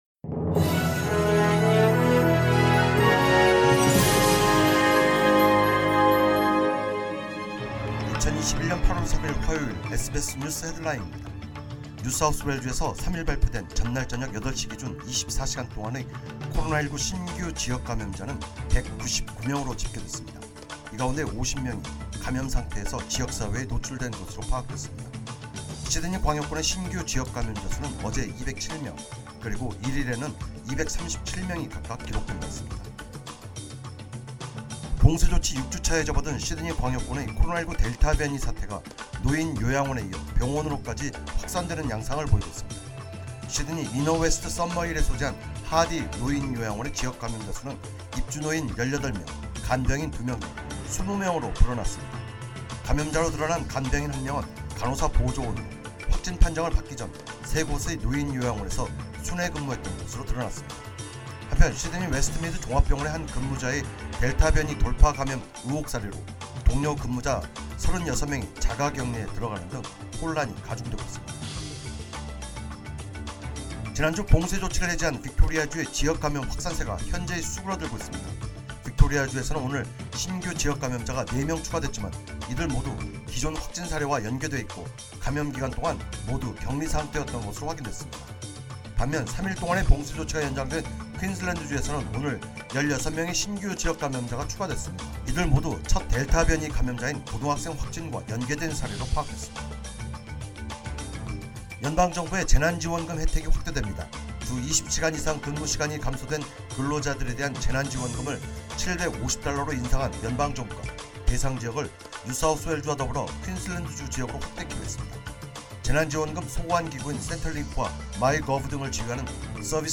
2021년 8월 3일 화요일 SBS 뉴스 헤드라인입니다.